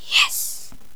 khanat-sounds-sources - Source files to create all the .wav used in the Khanat game
genie_ack5.wav